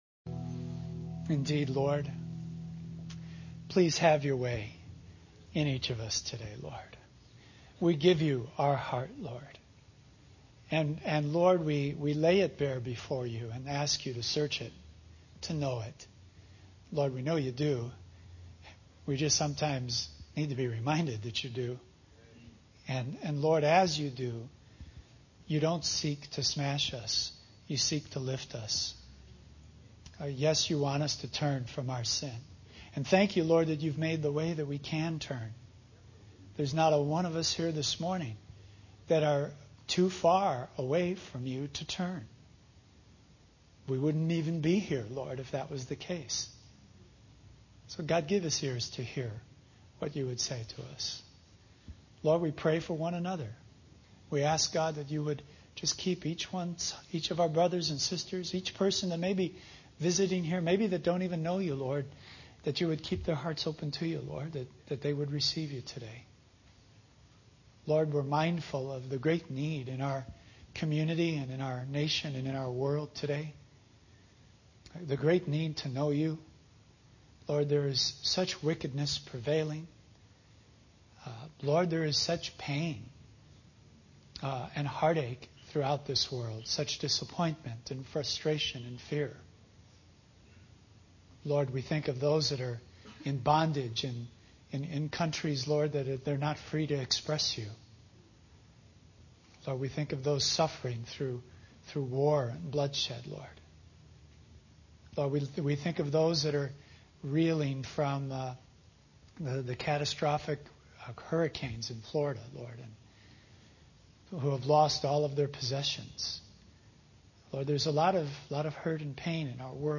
In this sermon, the speaker focuses on the first four letters of the book of Revelation. He emphasizes the importance of understanding the strengths and weaknesses mentioned in these letters and how they apply to our own lives and church.
He encourages the congregation to hold on to the hope of Christ's return and the ultimate justice that will be served. The sermon concludes with a prayer for those facing pain, heartache, and suffering in the world.